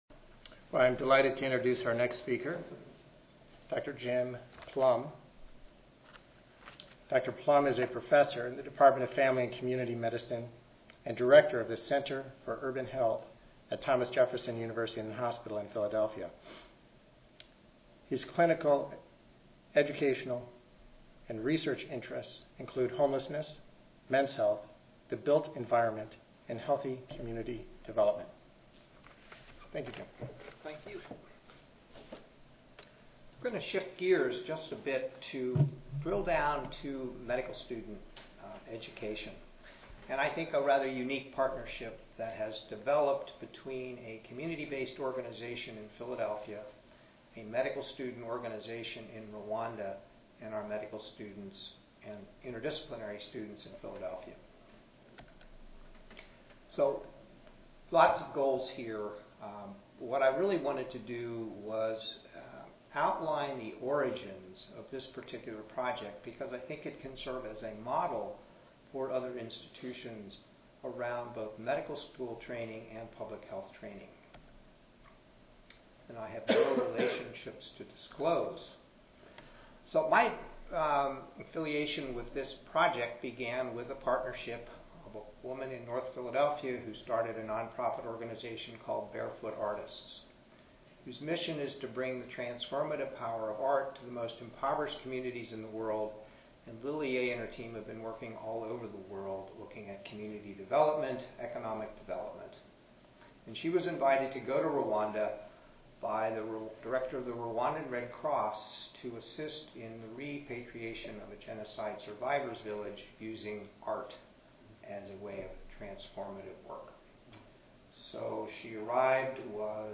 3355.0 Global health in medical education Monday, October 31, 2011: 2:30 PM Oral This experienced panel will discuss the role of Global health in US medical education, at the undergraduate and graduate medical education level. Expert commentary will be provided by discussants from public health and medical education.